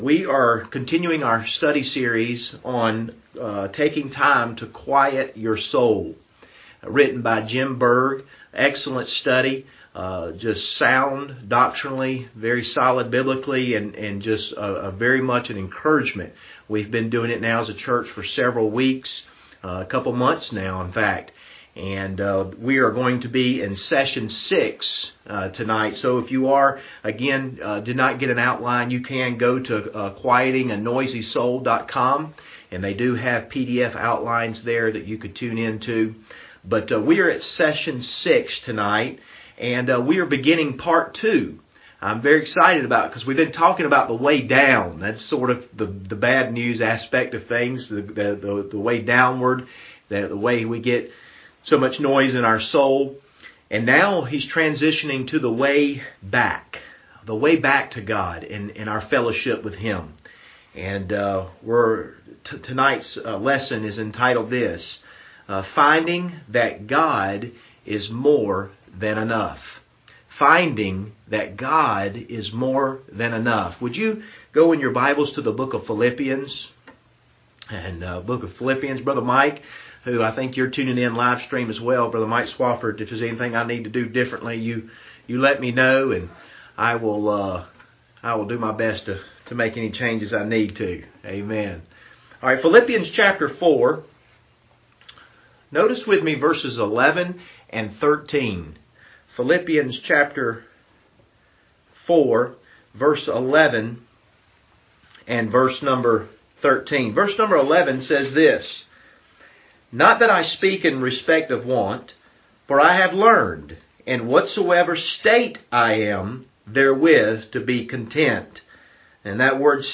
Service Type: Wednesday Evening Topics: Contentment